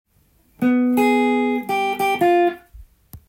G7を例にフレーズを作ってみました。
①は３度音から♭９の音に飛ぶ　ジャズギターリストなら
誰でも使っていそうなフレーズです。